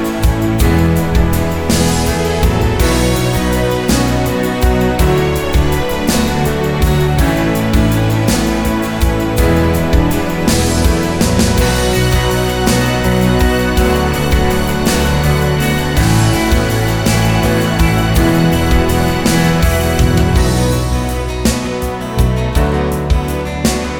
no Backing Vocals Duets 4:26 Buy £1.50